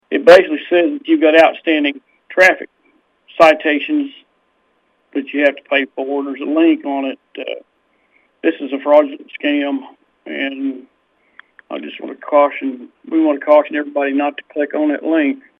Sheriff Scott Owen provides more details.